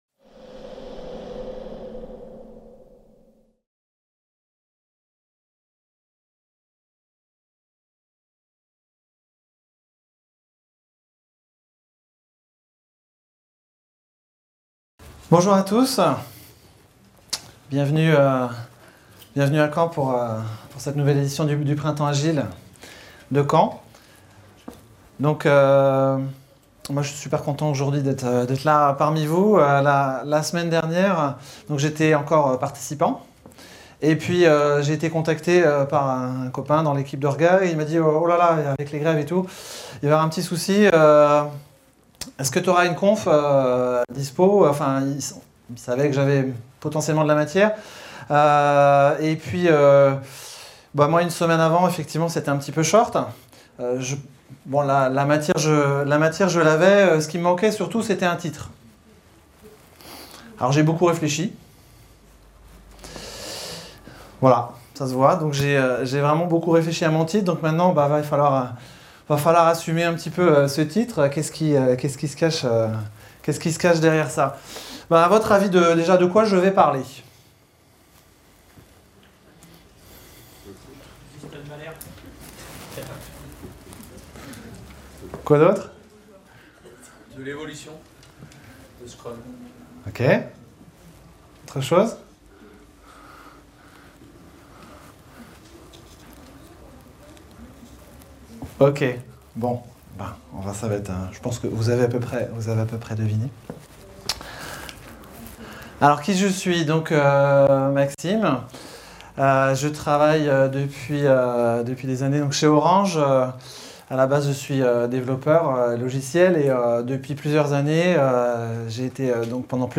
Type : Conférence Thém